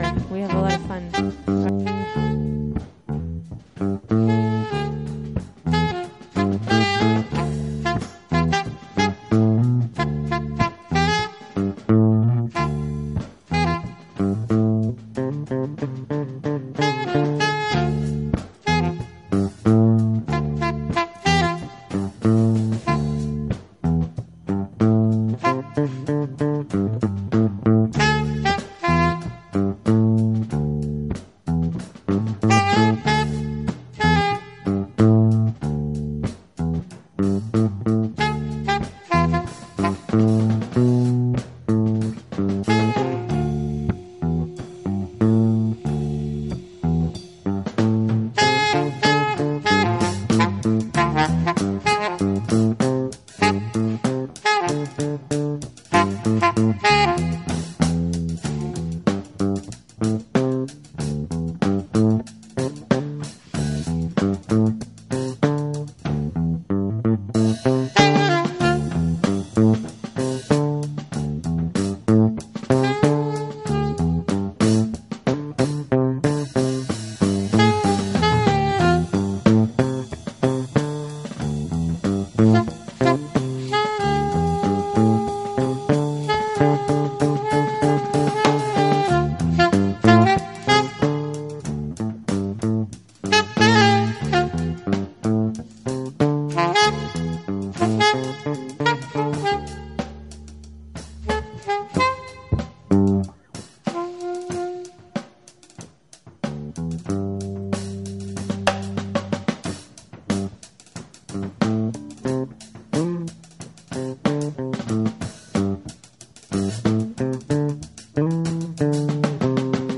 Live from The Red Dot Open Mic